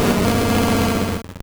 Cri d'Ursaring dans Pokémon Or et Argent.